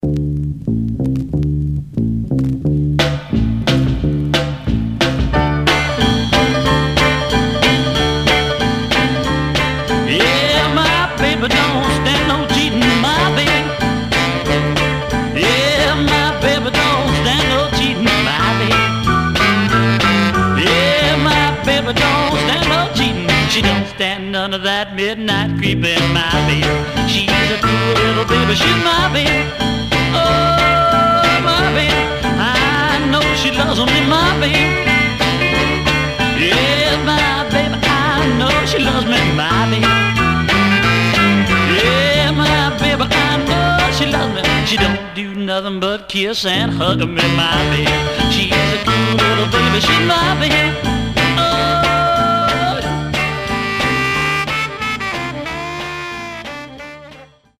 Garage, 60's Punk ..........👈🏼 Condition